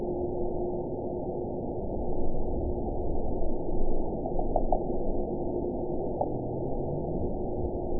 event 917457 date 04/03/23 time 20:48:32 GMT (2 years, 1 month ago) score 7.69 location TSS-AB03 detected by nrw target species NRW annotations +NRW Spectrogram: Frequency (kHz) vs. Time (s) audio not available .wav